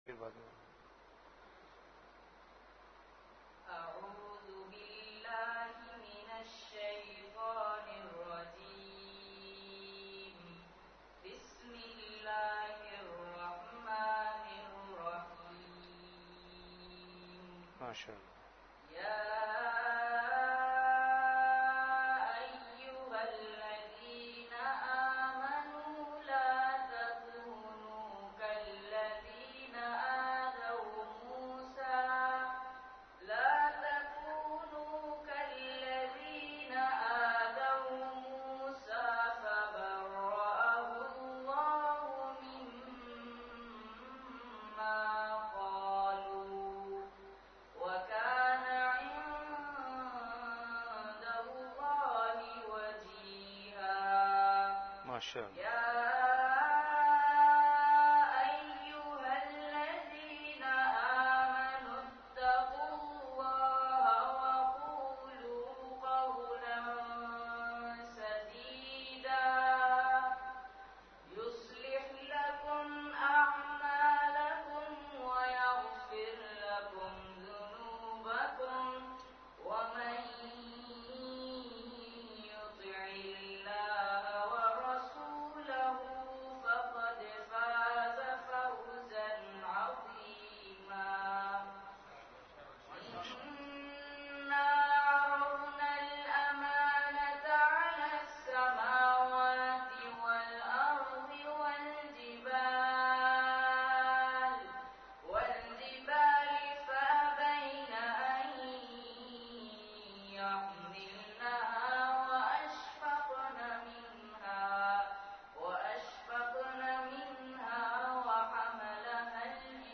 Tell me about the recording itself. Majlis-e-Zikr · Jamia Masjid Bait-ul-Mukkaram, Karachi